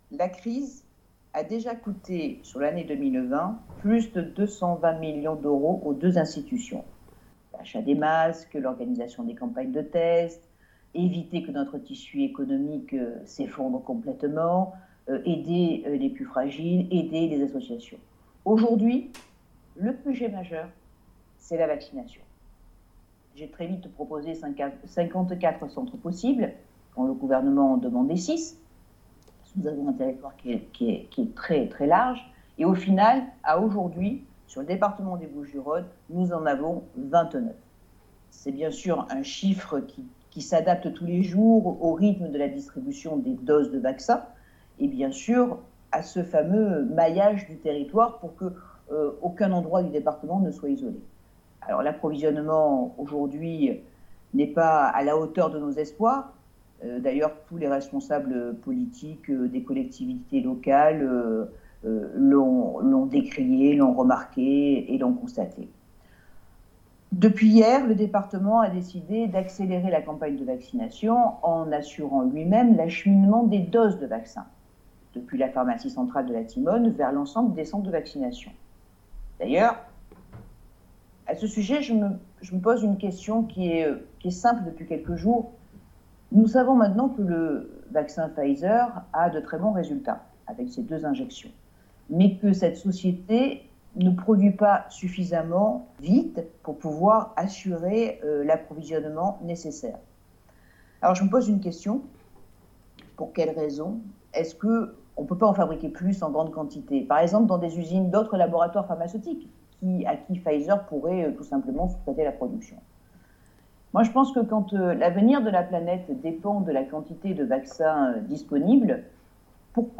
Vœux à la presse de Martine Vassal : -Nous avons réalisé des promesses des années 70-